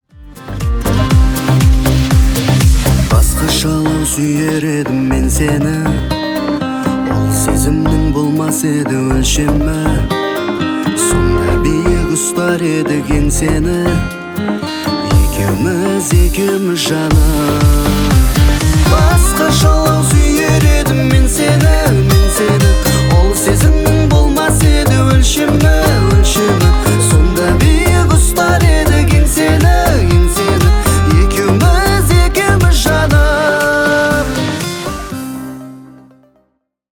Казахские # спокойные # грустные